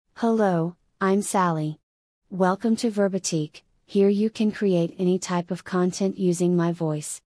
SalliFemale US English AI voice
Salli is a female AI voice for US English.
Voice sample
Listen to Salli's female US English voice.
Female
Salli delivers clear pronunciation with authentic US English intonation, making your content sound professionally produced.